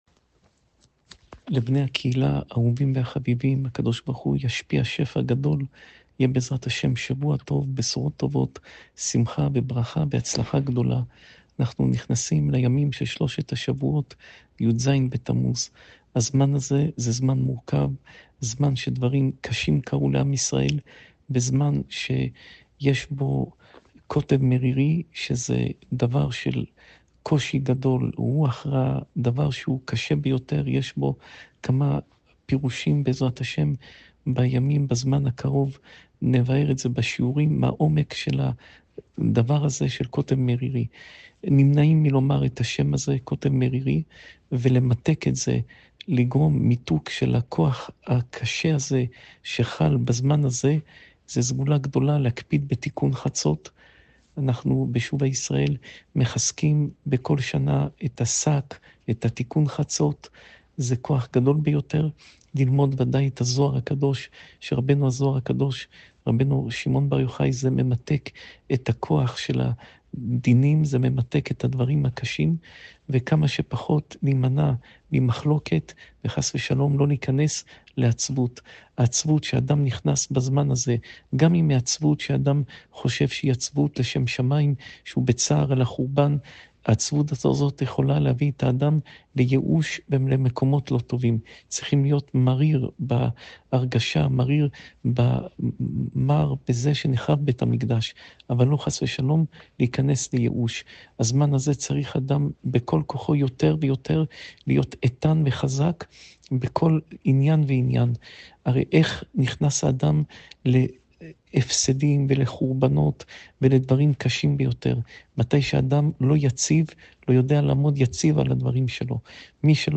שעורי תורה מפי הרב יאשיהו יוסף פינטו
שעור תורה מפי הרב פינטו